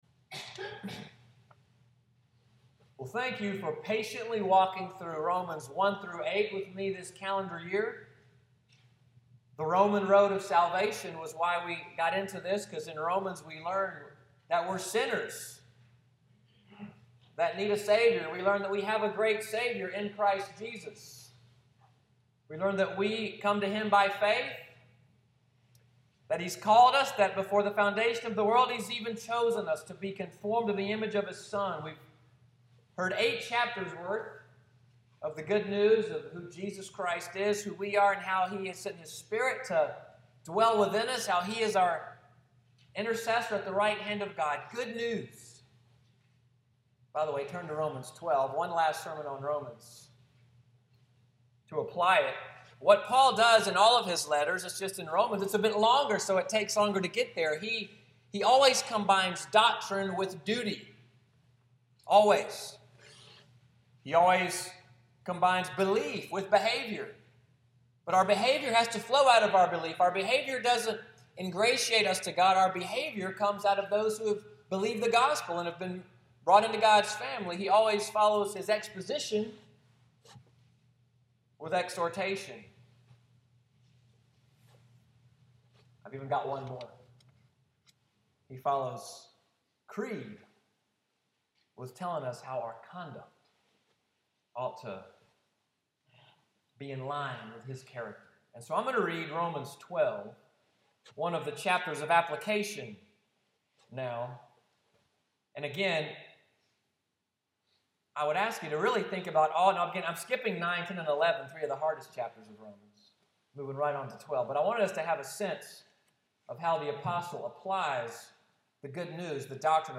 Sunday’s sermon, “How Then Shall We Live?”